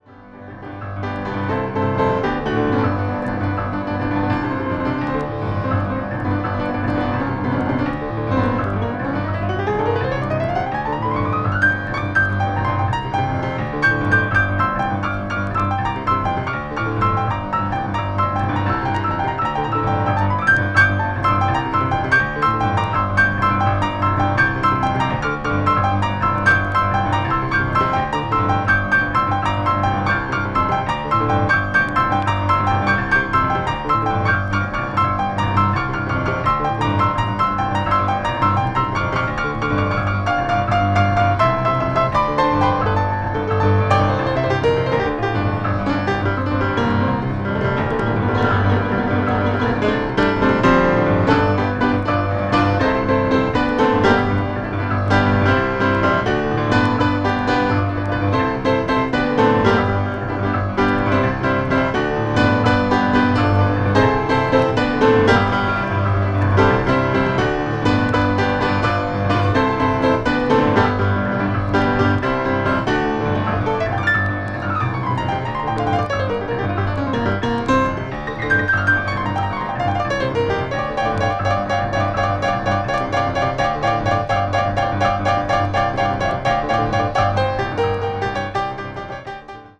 media : VG/VG(細かい擦れ/スリキズによるチリノイズが入る箇所あり)
全編ピアノ・ソロを収録した作品で
contemporary jazz   deep jazz   piano solo